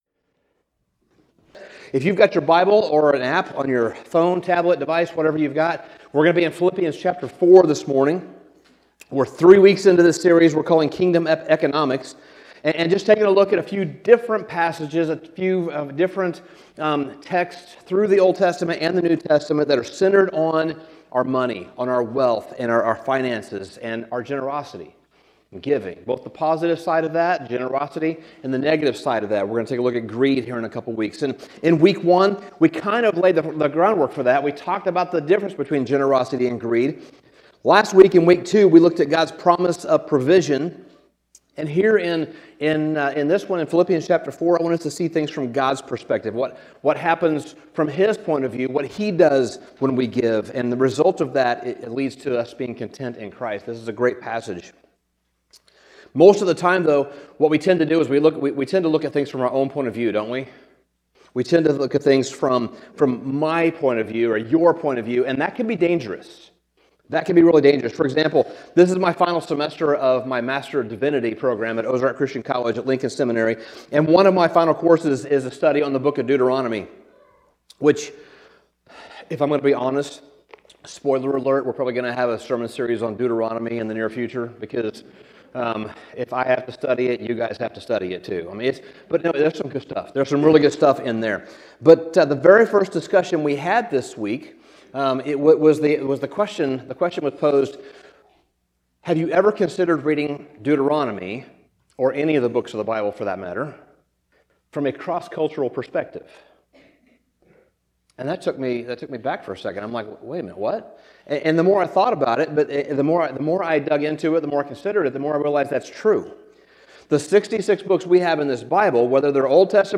Sermon Summary God notices. That simple truth sits quietly beneath Paul’s words in Philippians 4:10-20.